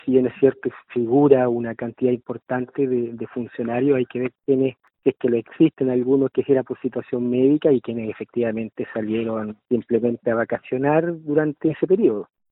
Su par, Freddy Cartes, indicó que es necesario conocer en detalle qué situación vivió cada trabajador para presentar esa licencia médica.